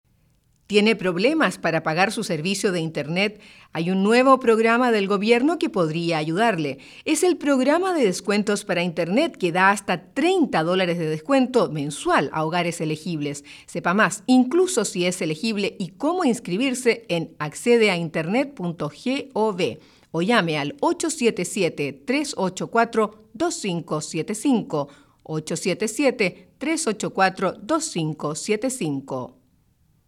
acp_psa_spanish.mp3